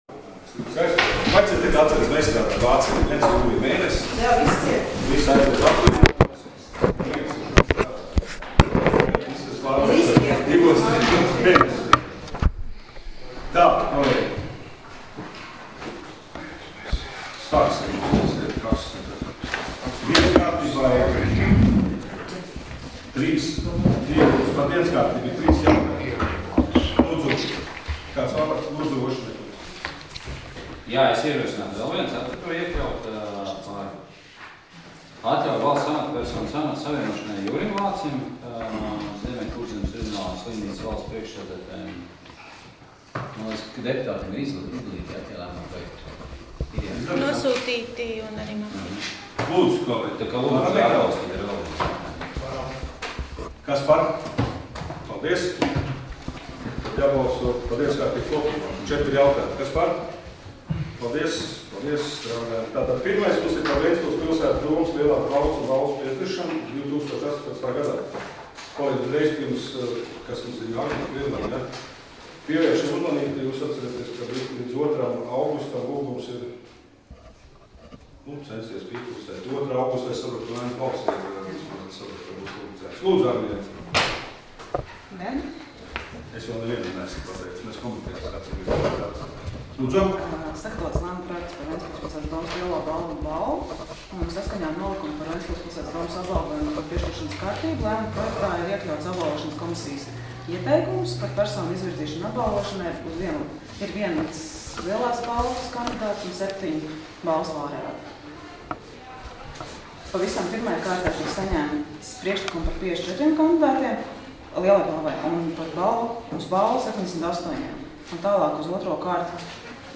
Domes sēdes 20.07.2018. audioieraksts